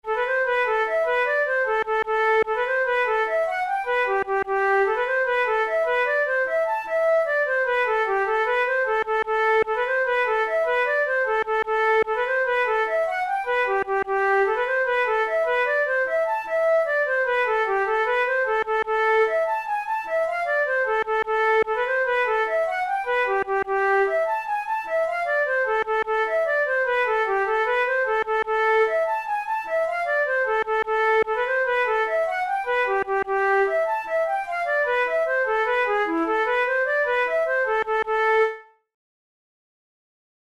Traditional Irish jig